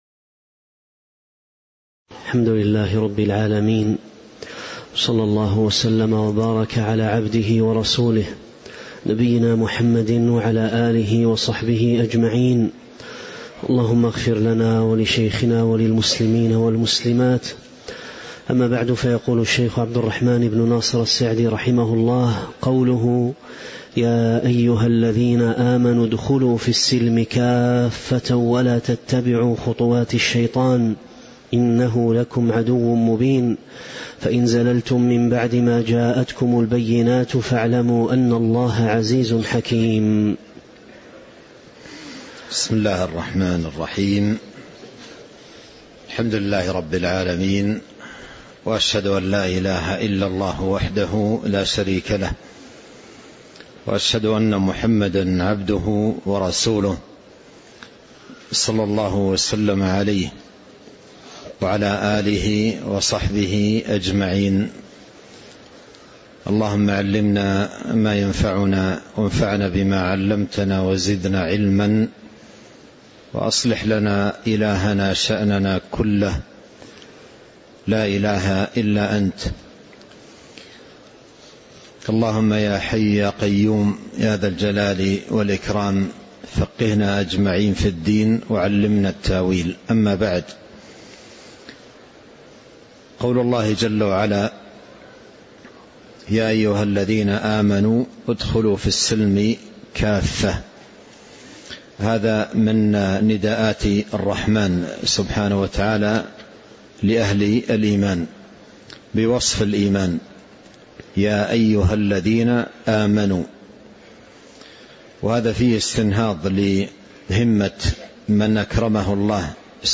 تاريخ النشر ٢٩ رجب ١٤٤٦ هـ المكان: المسجد النبوي الشيخ